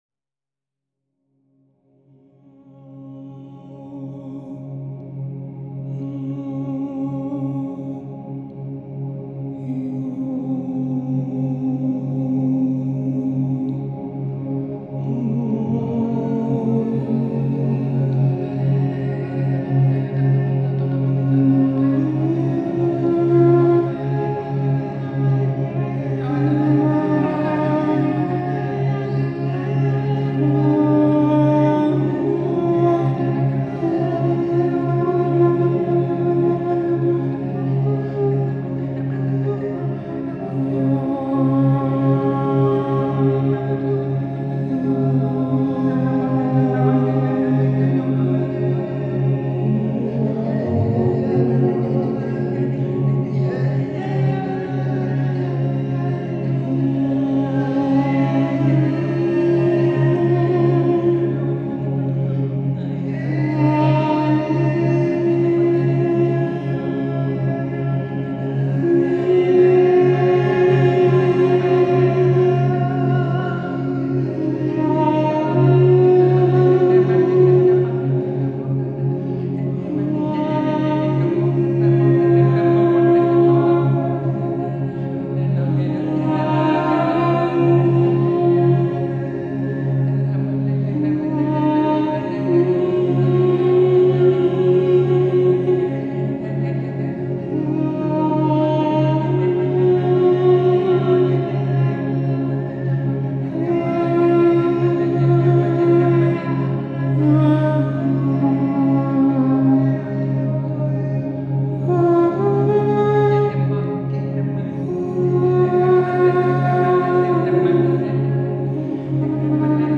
이번 메시지에서는 미묘한 빛 에너지의 흐름을 통해 활력을 회복하는 동시에 내면의 인식을 향상시킬 수 있는 새로운 사운드 명상을 공유하고자 합니다.